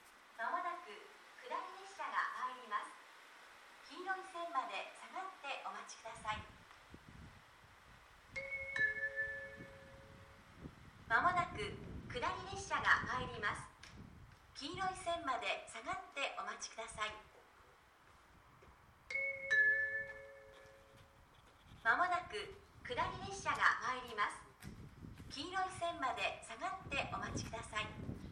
この駅では接近放送が設置されています。
１番線奥羽本線
接近放送普通　秋田行き接近放送です。